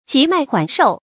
急脈緩受 注音： ㄐㄧˊ ㄇㄞˋ ㄏㄨㄢˇ ㄕㄡˋ 讀音讀法： 意思解釋： 見「急脈緩灸」。